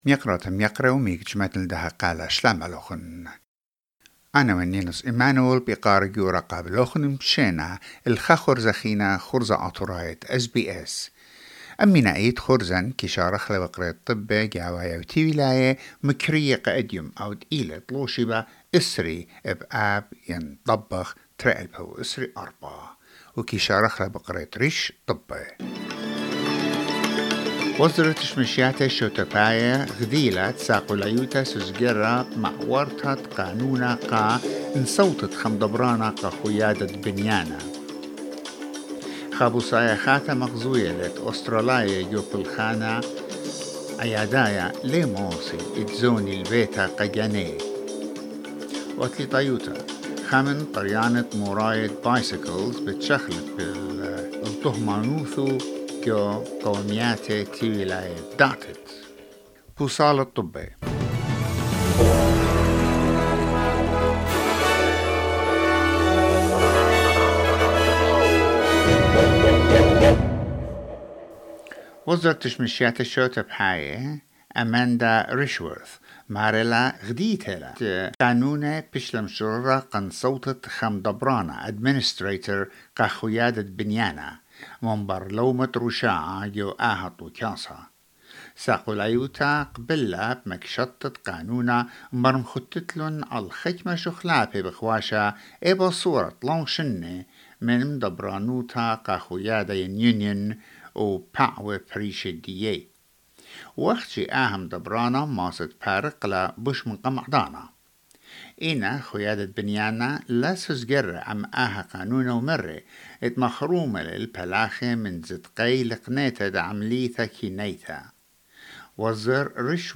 SBS Assyrian news bulletin: 20 August 2024